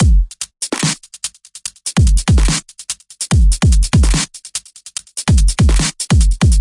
蔑视这一点 桥 808 145bpm
描述：陷阱和科幻的结合。沉重的打击和神秘感。使用这些循环来制作一个超出这个世界范围的爆炸性节目吧 :)D小调
标签： 145 bpm Trap Loops Bass Wobble Loops 2.23 MB wav Key : D
声道立体声